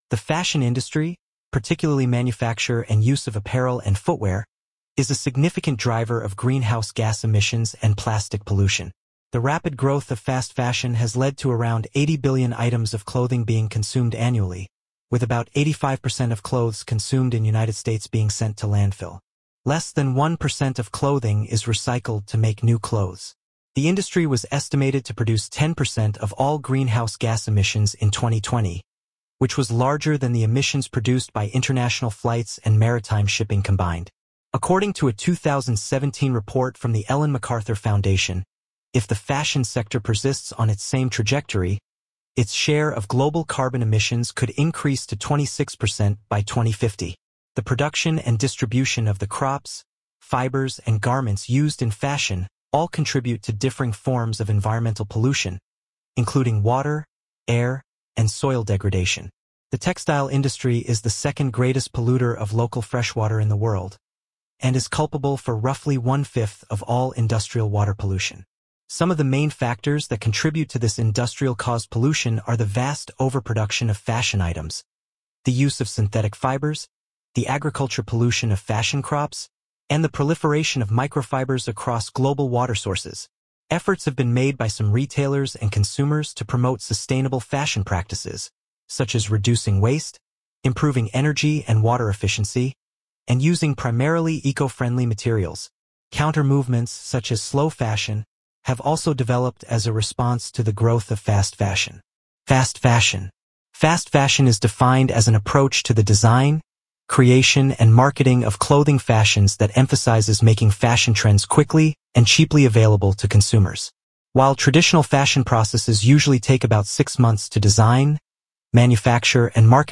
Environmental impact of fashion narrated by AI-generated voice using SoniTranslate as described here
voice en-US-BrianMultilingualNeural-Male